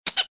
На этой странице собраны разнообразные звуки бурундуков — от веселого стрекотания до любопытного писка.
Писк